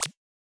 generic-select.wav